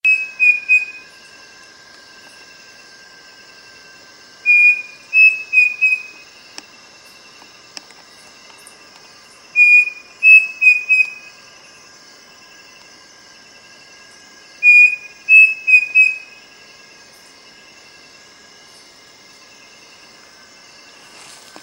Pavonine Cuckoo (Dromococcyx pavoninus)
Life Stage: Adult
Location or protected area: Reserva Privada y Ecolodge Surucuá
Condition: Wild
Certainty: Photographed, Recorded vocal